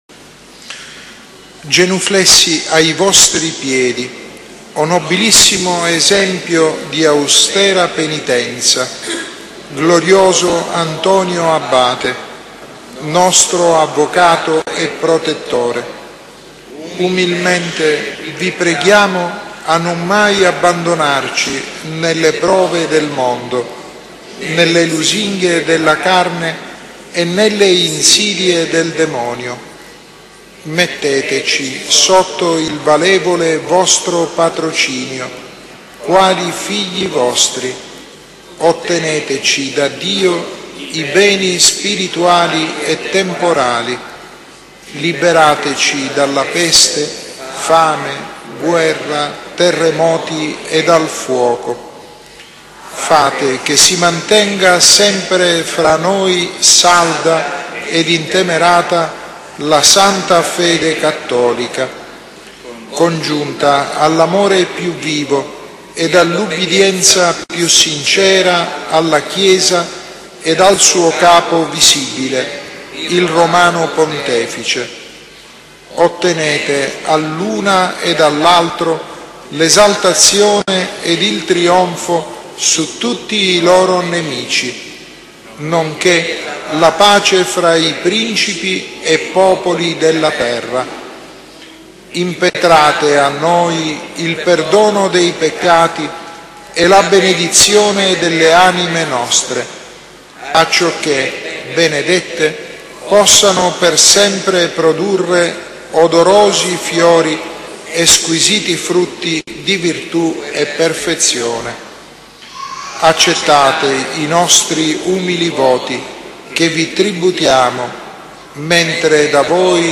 Preghiera a Sant'Antonio Abate
Preghiera a S. Antonio Abate.mp3